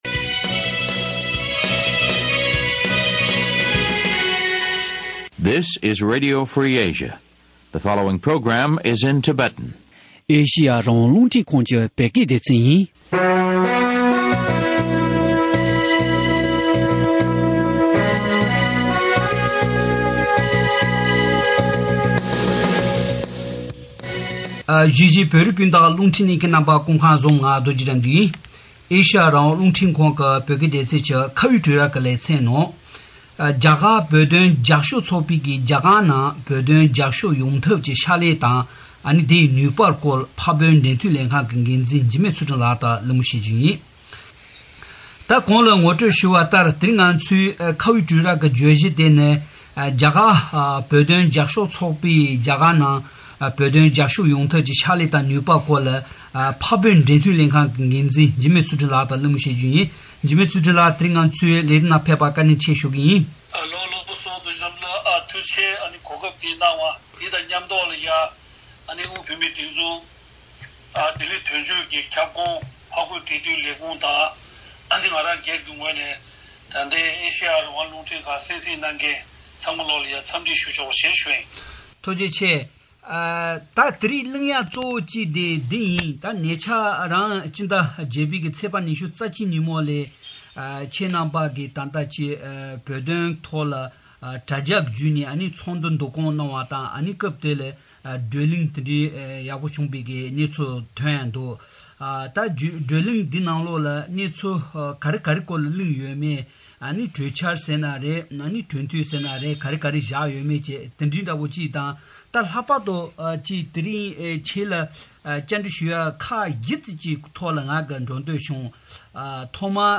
དབར་གླེང་གནང་བར་གསན་རོགས་ཞུ།།